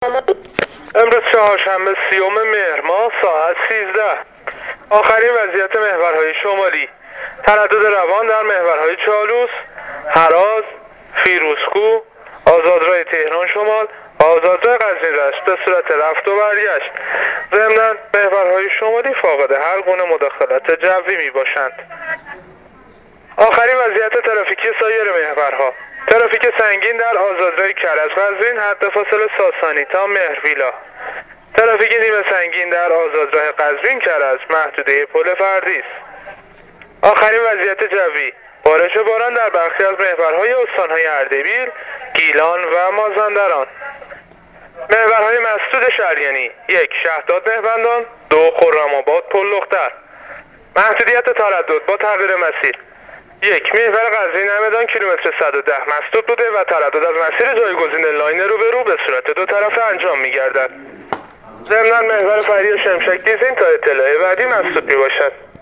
گزارش رادیو اینترنتی از وضعیت ترافیکی جاده‌ها تا ساعت ۱۳ چهارشنبه ۳۰ مهر